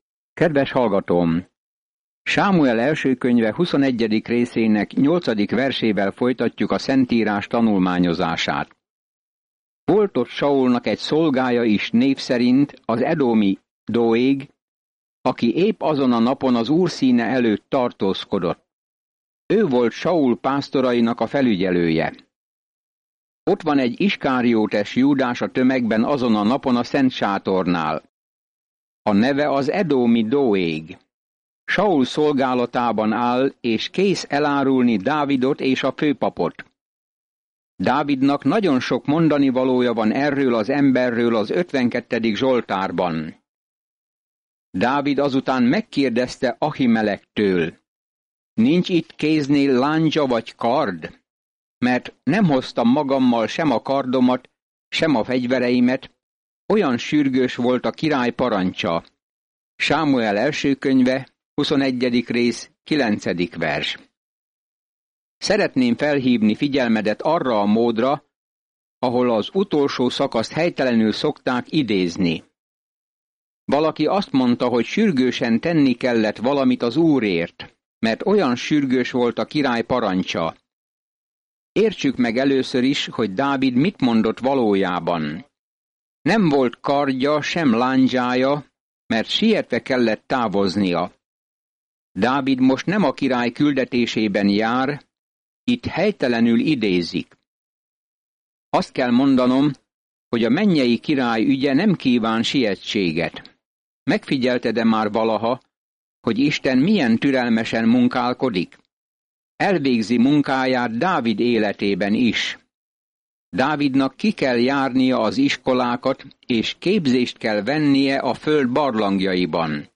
Szentírás 1Sámuel 21:8-15 1Sámuel 22:1-18 Nap 12 Olvasóterv elkezdése Nap 14 A tervről Először Sámuel Istennel kezdi, mint Izrael királyát, és folytatja a történetet arról, hogyan lett Saul, majd Dávid király. Napi utazás Első Sámuelen keresztül, miközben hallgatod a hangos tanulmányt, és olvasol válogatott verseket Isten szavából.